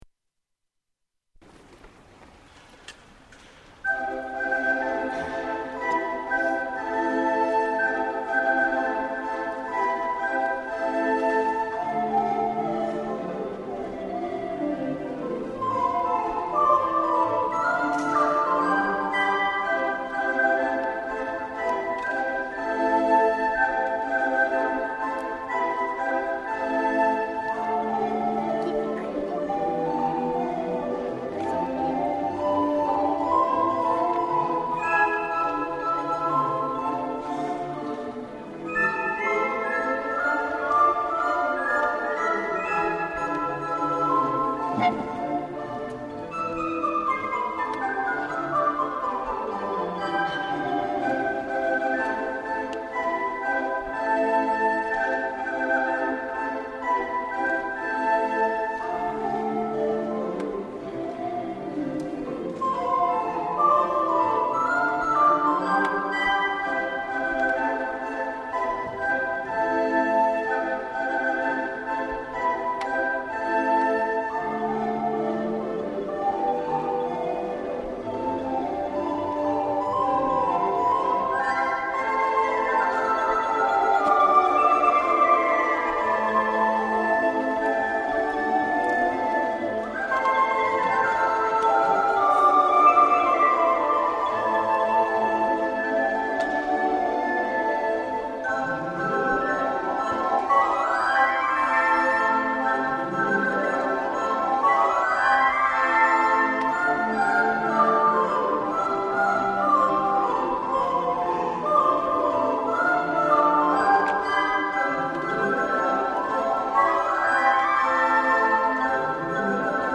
Ungdomsblokkfløytistene Uranienborg og St.Sunniva.
Fra en konsert i Oslo Rådhus 12. mars 2007.